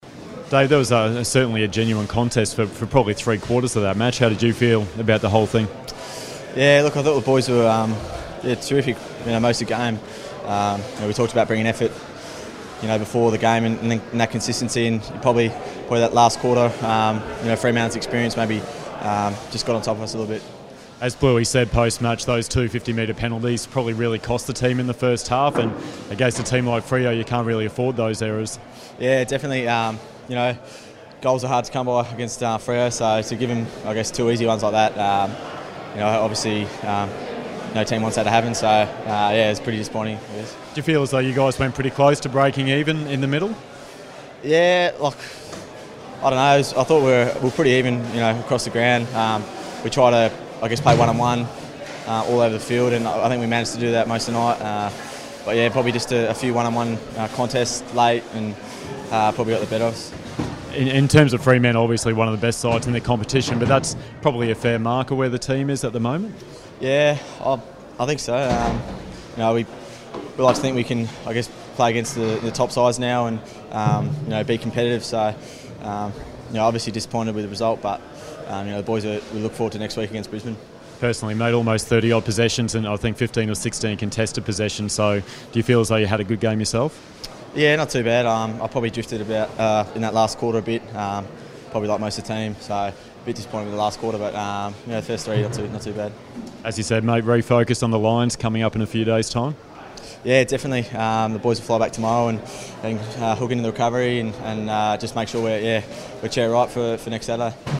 Round 2 post-match interview